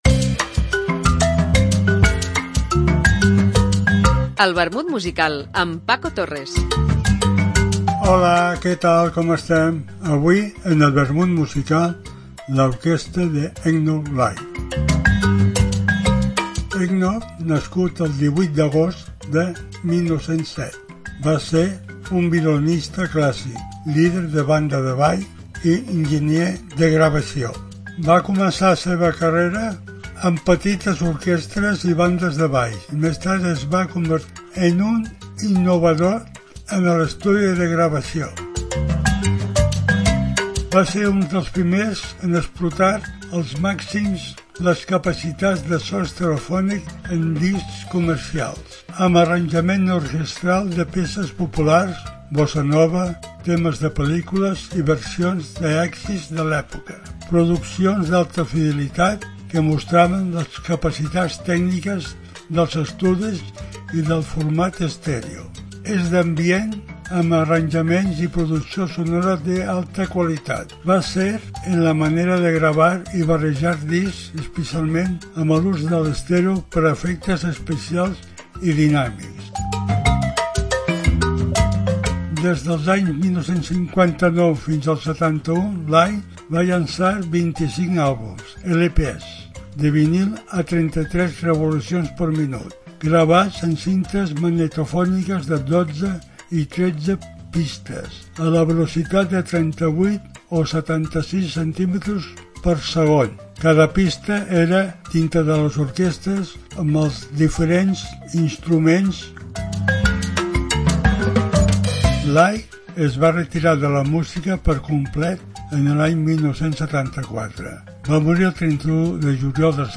Una apunts biogràfics acompanyats per una cançó.